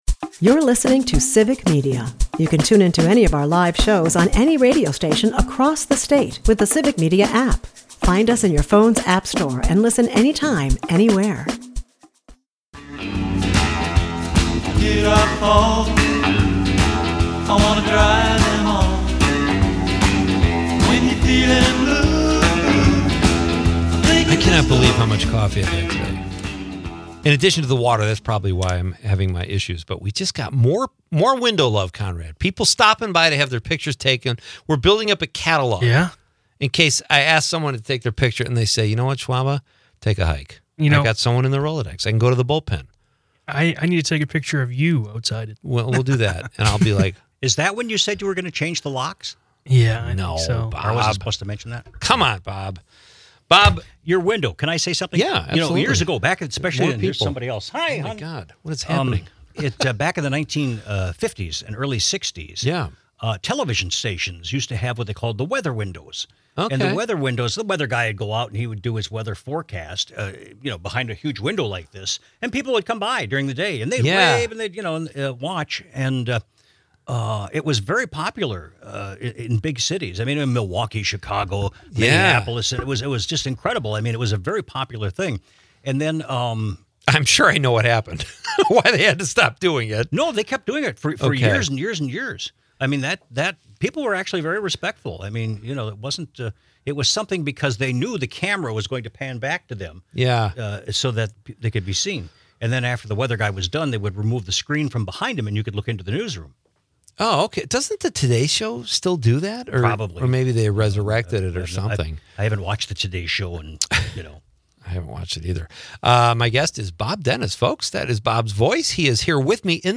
calling in from Texas, dishes out mouthwatering BBQ tips and tailgating advice, emphasizing the importance of using good wood and the 'low and slow' method. With humorous interludes about gym locker room etiquette and favorite movies, this episode is a flavorful mix of entertainment and culinary delight.